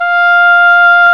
WND OBOE3 F5.wav